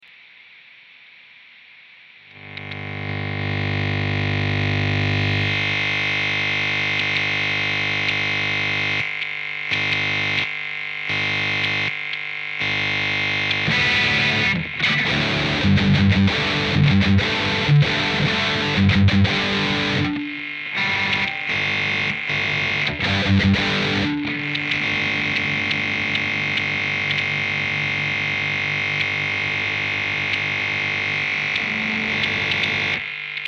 Brummen via Steckdose & E-Gitarre (Lösungssuche bisher erfolglos)